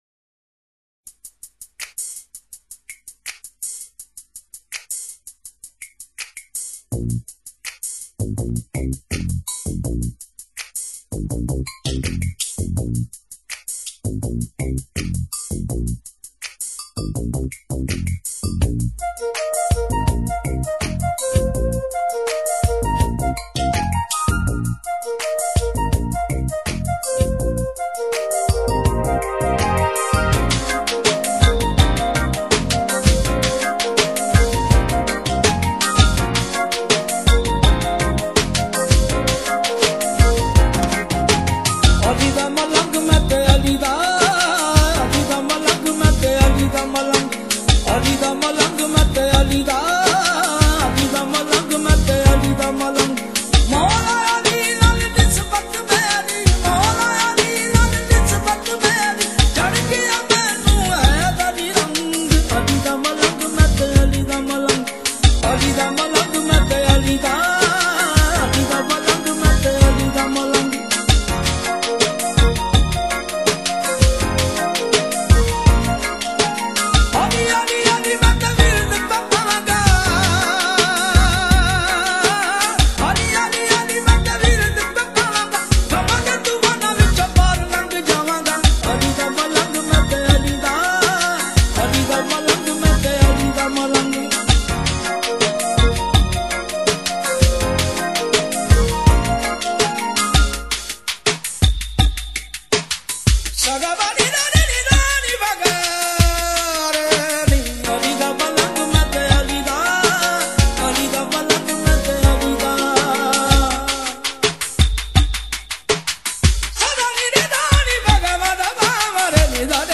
Pakistani Qawwali MP3 Collection